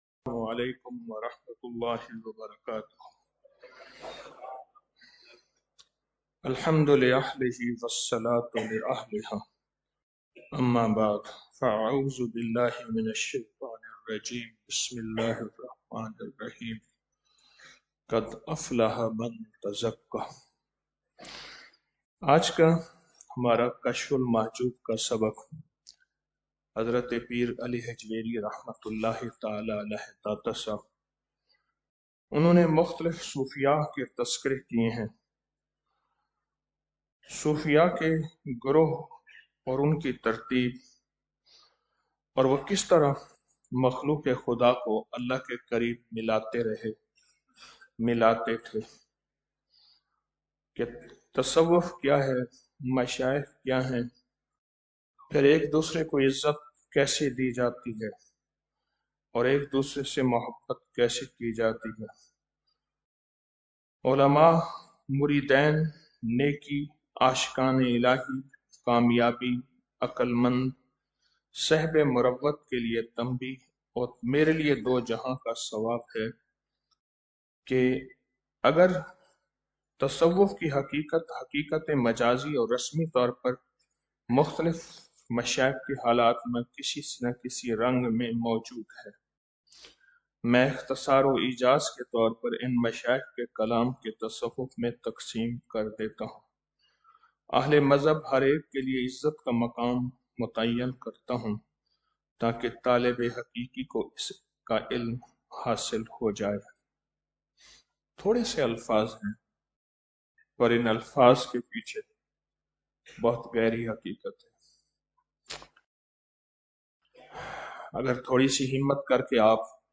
Audio Speech - 01 Ramadan After Salat Ul Fajar - 22 March 2025